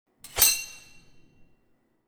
SWORD_03.wav